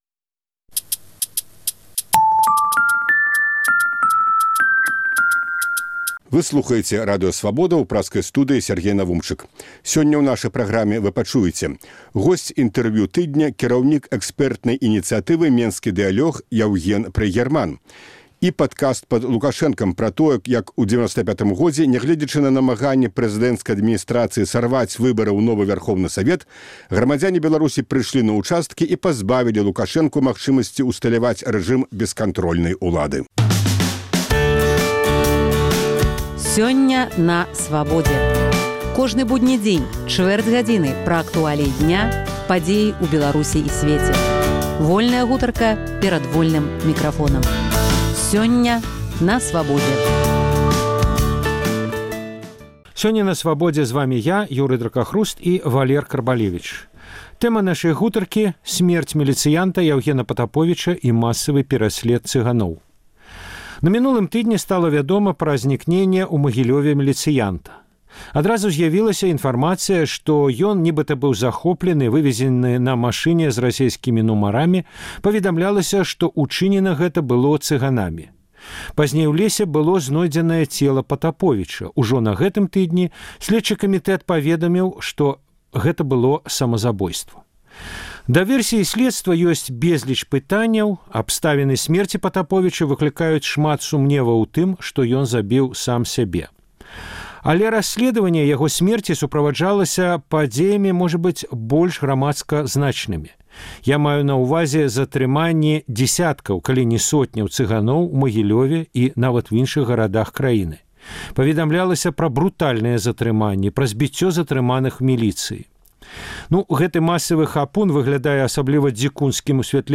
Інтэрвію тыдня.